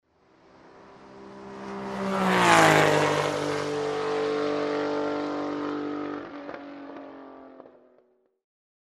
Звуки гоночного автомобиля